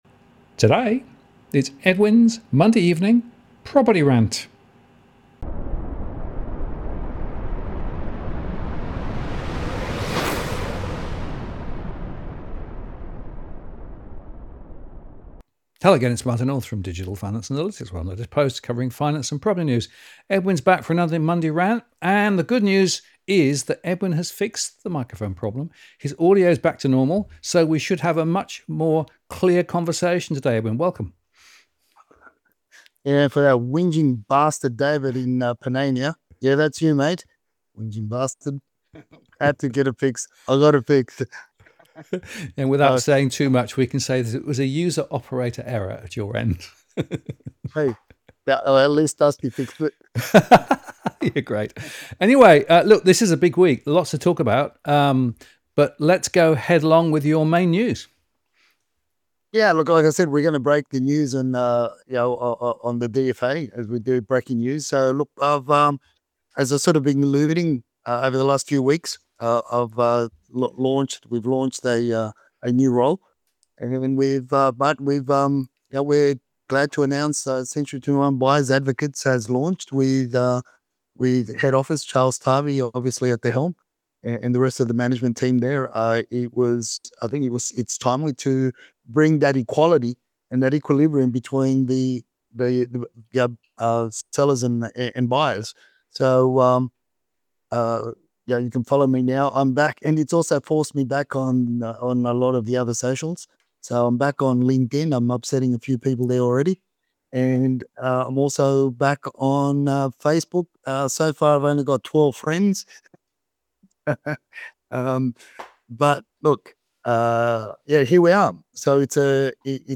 audio is returned to pristine condition (yeh)